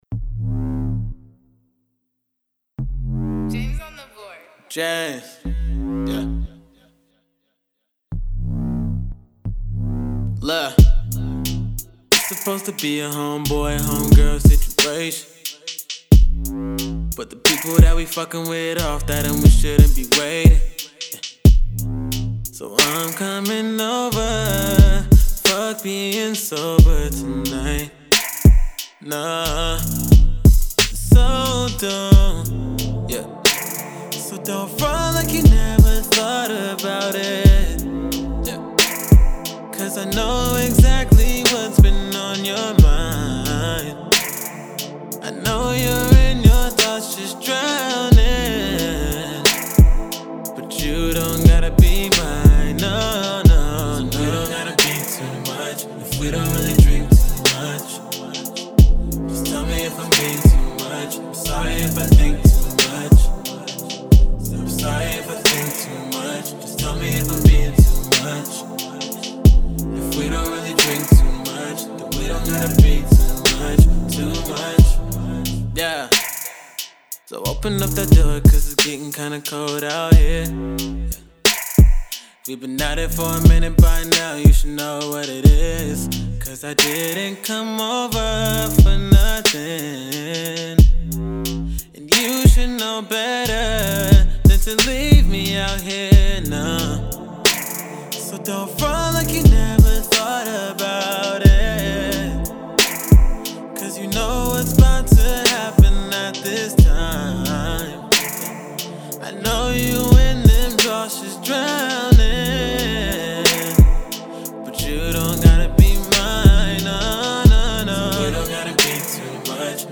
Hip-Hop/Rap R&B